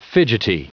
Prononciation du mot fidgety en anglais (fichier audio)
Prononciation du mot : fidgety